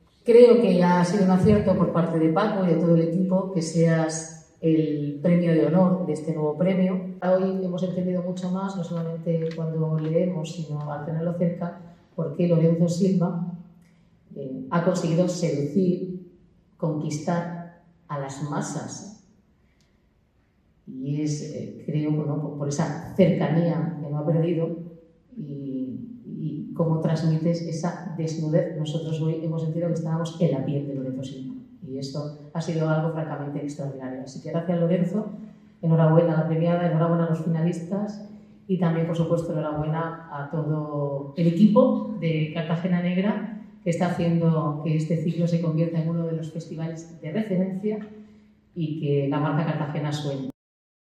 El acto, celebrado en el centro cultural 'El Luzzy', contó con la presencia de la alcaldesa, Noelia Arroyo.
Enlace a Palabras de la alcaldesa, Noelia Arroyo